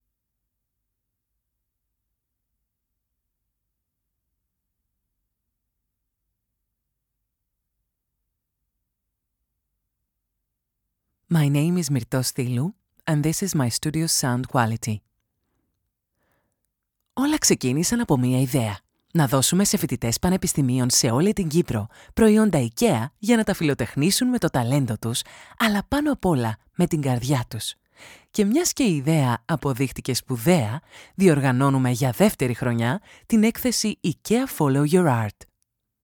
Female
Approachable, Assured, Authoritative, Character, Children, Confident, Conversational, Corporate, Deep, Energetic, Engaging, Friendly, Natural, Reassuring, Smooth, Versatile, Warm
Microphone: MKH 416 Sennheiser Shotgun
Audio equipment: Professionally sound-proofed home studio room, RME Fireface UCX II, Audio-Technica ATH M50x Headphones, Kali Audio studio monitors